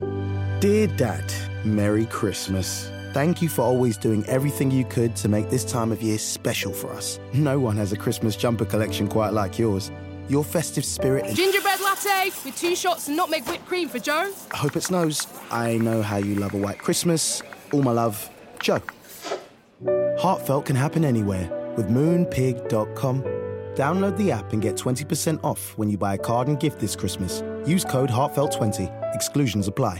20/30's London, Fresh/Funny/Charismatic
Moonpig | Voice-Over